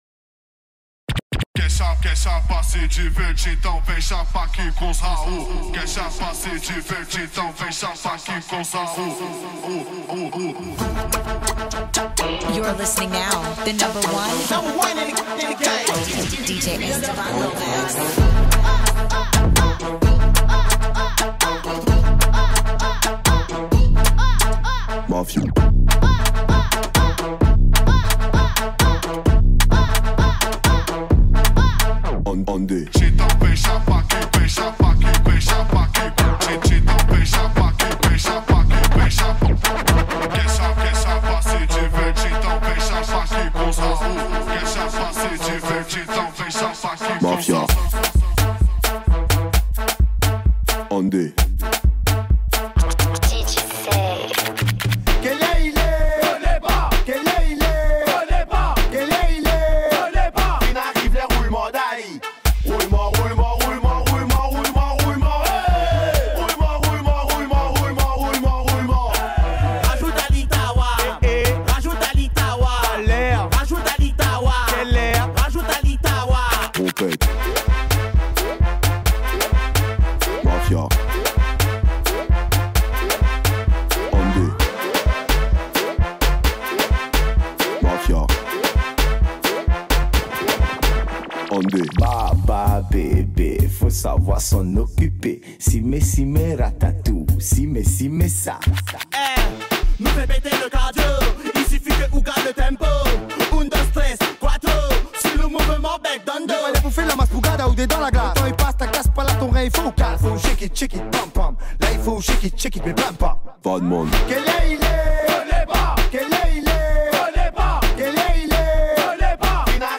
paris_ambiance.mp3